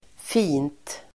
Uttal: [fin:t]